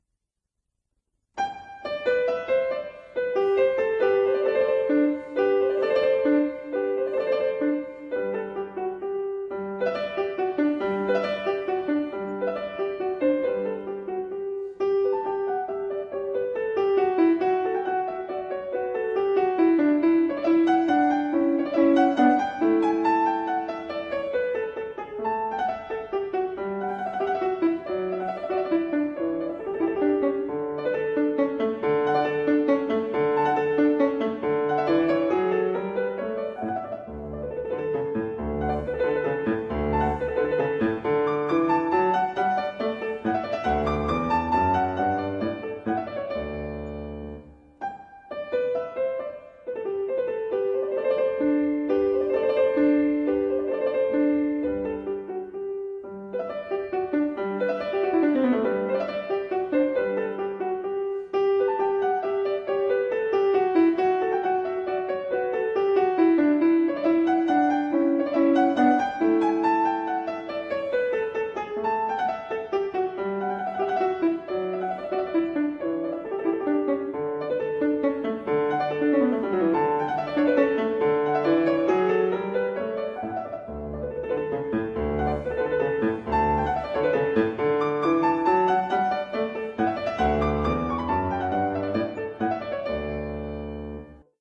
Recorded at Fazioli Concert Hall, Sacile (Italy), 2012
Fazioli Grand Piano model F728
Harpsichord Sonatas